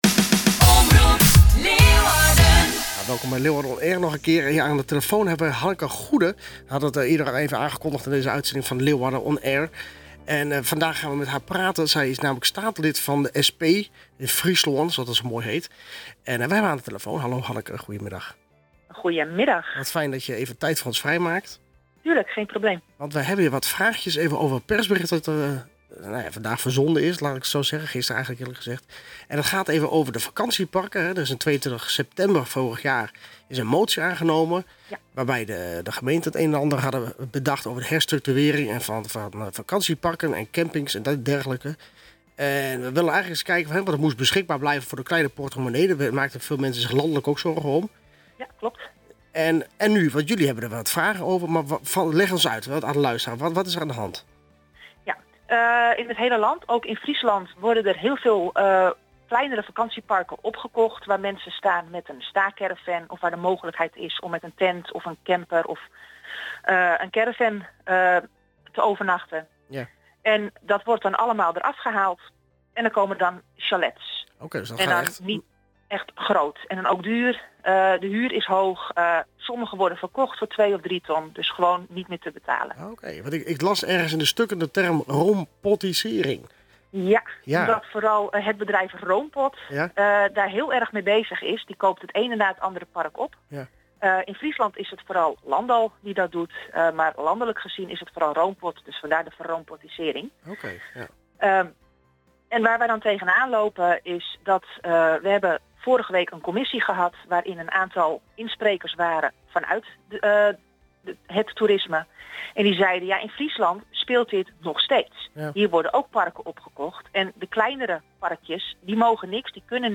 Gesprek met Hanneke Goede